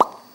描述：Single Plop已经上传但数量增加了
标签： 扑通 声音设计 FX 声音
声道立体声